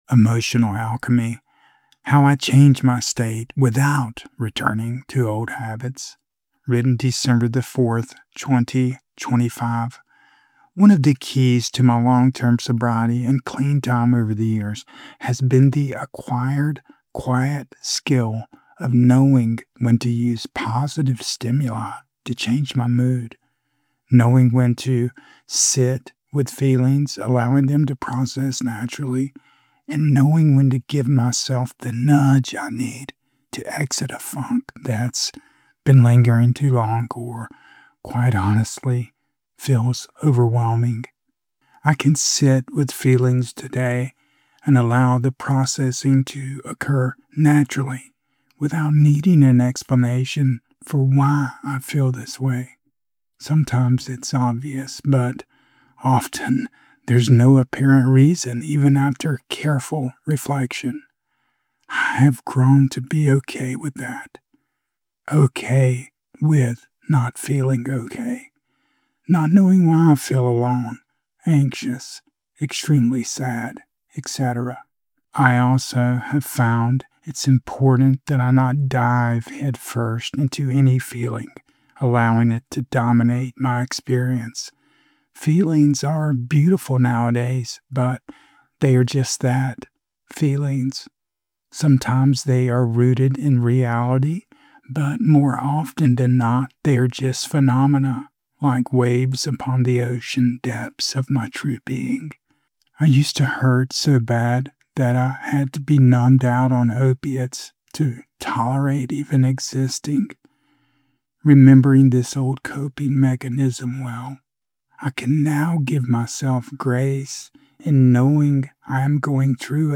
Spoken Audio